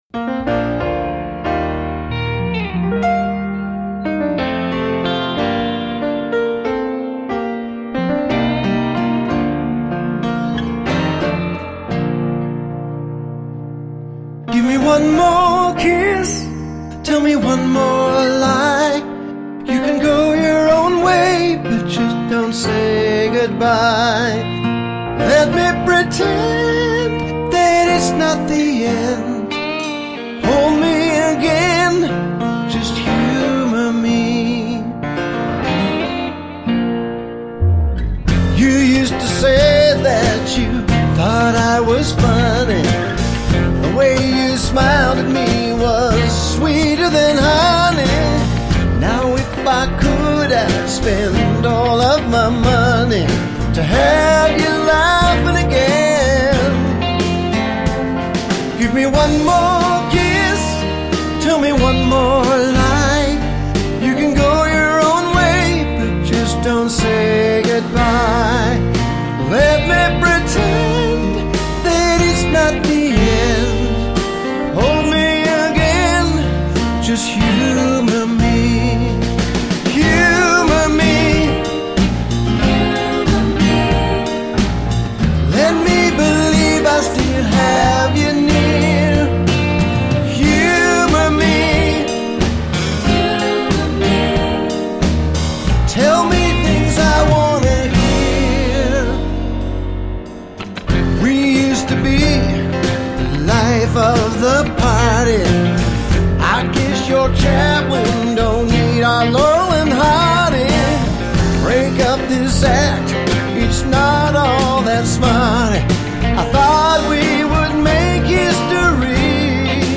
piccolo trumpet solo